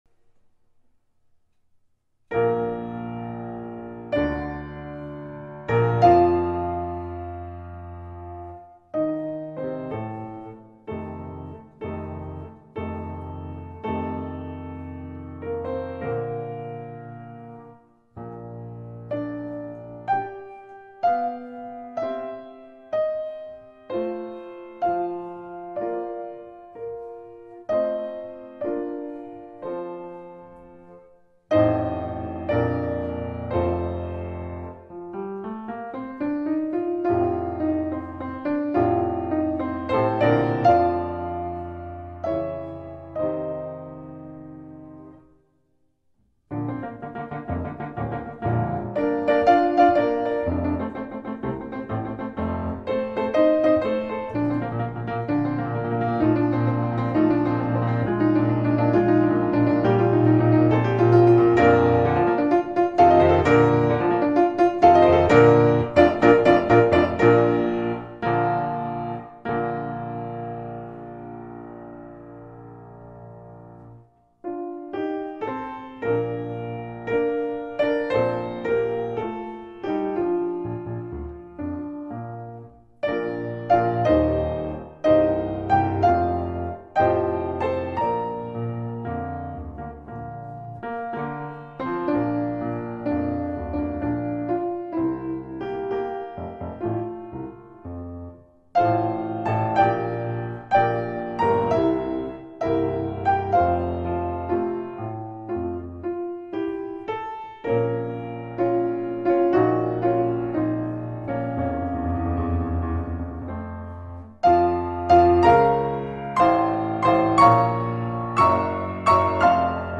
на фортепиано